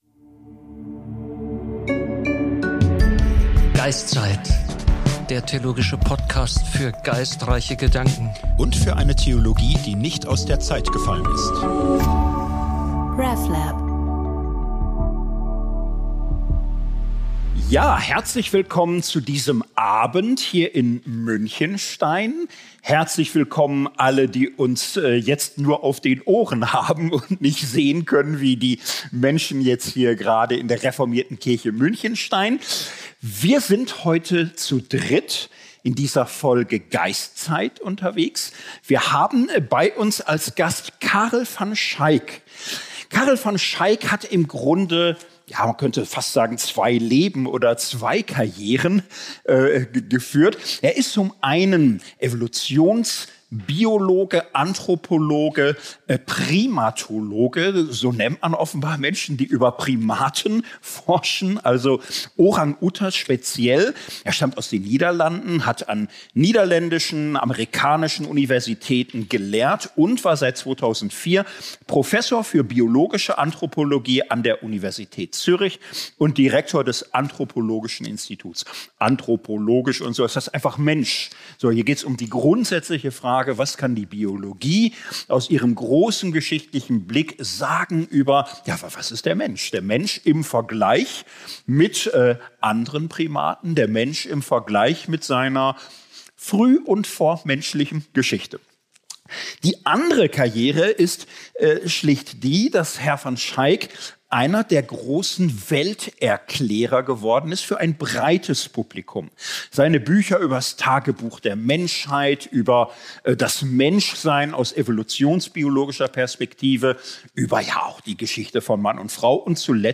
In einem Live-Podcast in Münchenstein